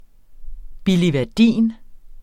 Udtale [ bilivæɐ̯ˈdiˀn ]